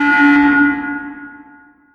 Sound Effects
Among Us Sabotage Airship